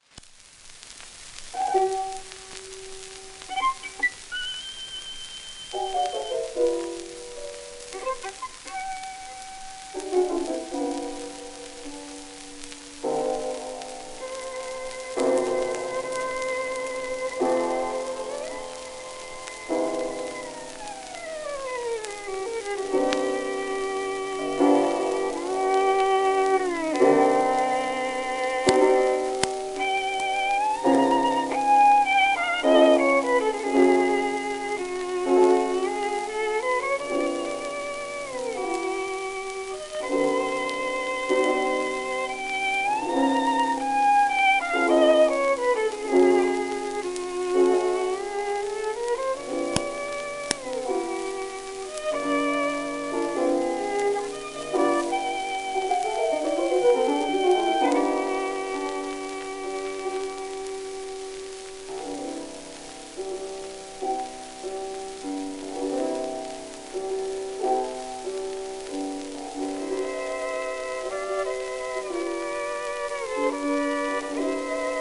w/ピアノ
12インチ片面盤
ラッパ吹き込み時代のコロムビアは80rpm
旧 旧吹込みの略、電気録音以前の機械式録音盤（ラッパ吹込み）